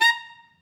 Clarinet
DCClar_stac_A#4_v3_rr1_sum.wav